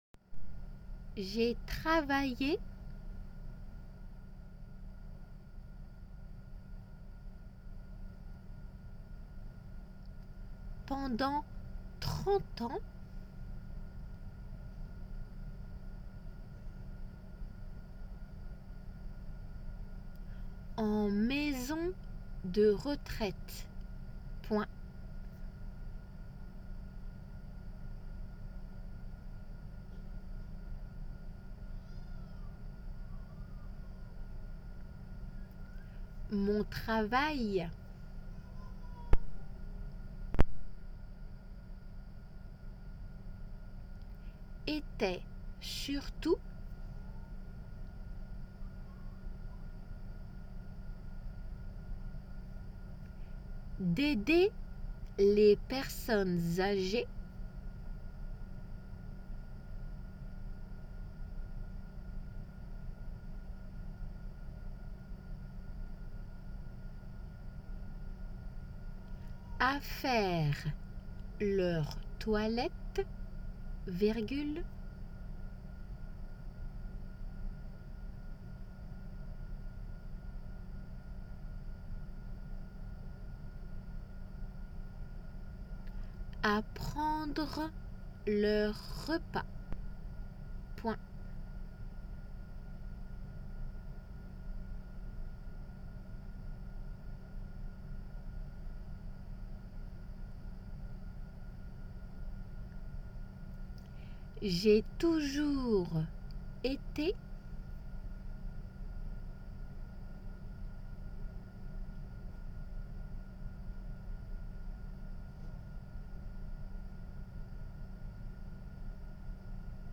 仏検2級　デイクテ　練習ー２音声
実際の仏検では普通の速さで読まれた後にデイクテ用の音声が流れます。
又デイクテの速さも多少の差があります。